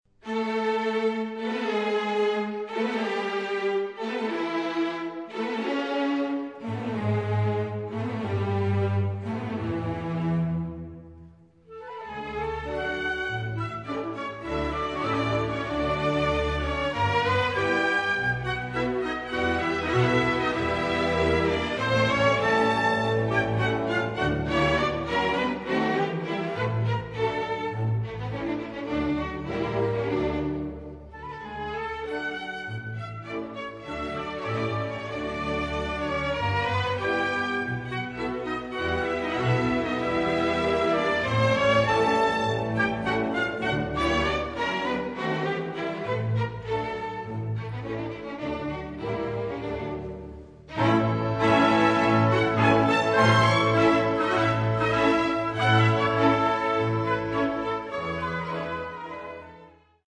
The recording was made in Budapest, in 1984..
III/5 Balett (Csárdás - Palotás)